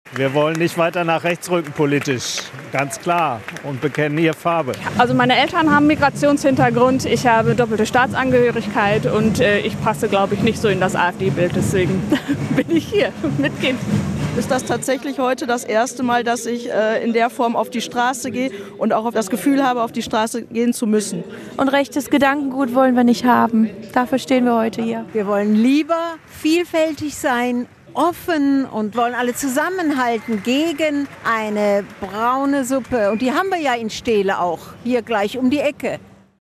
demo-steele-gegen-rechts.mp3